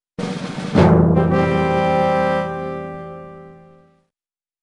warwarning.wav
乐器类/重大事件短旋律－宏大/warwarning.wav